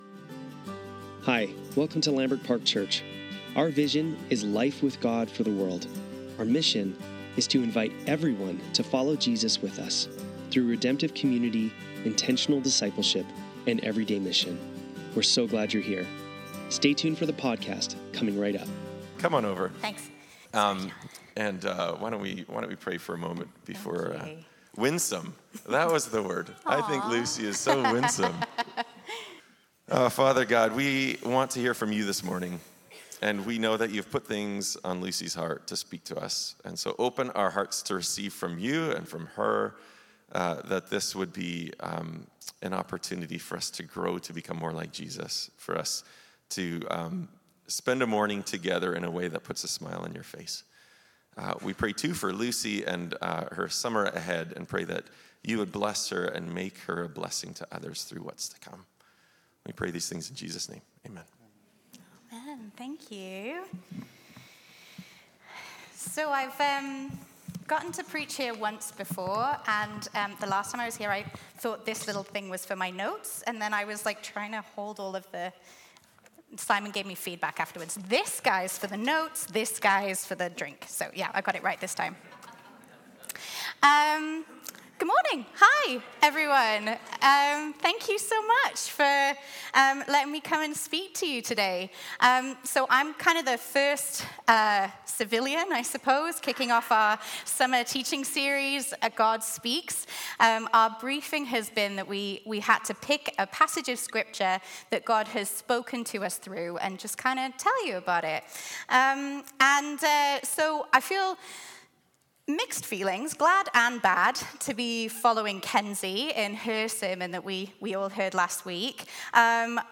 Sermons | Lambrick Park Church